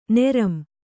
♪ nera